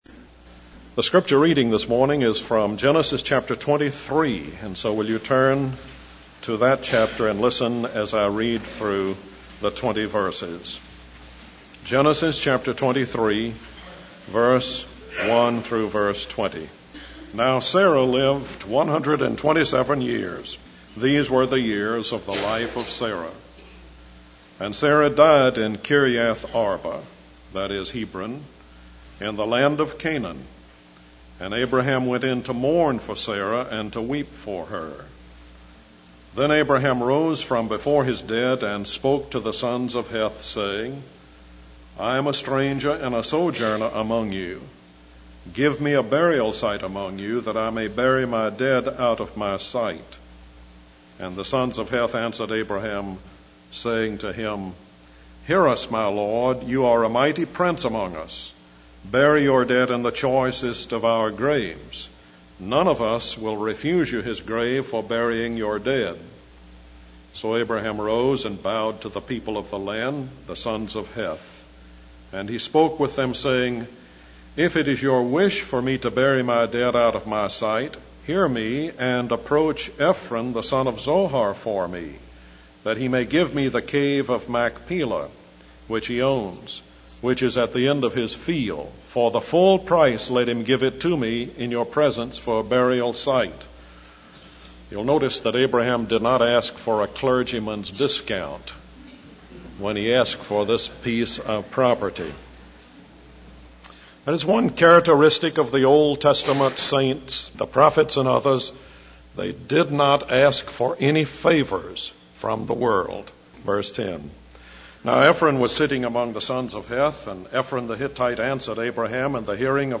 In this sermon, the preacher focuses on Genesis chapter 23 and the lessons it offers. He emphasizes the mode of life of a person of faith in dealing with the material world, using Abraham as an example.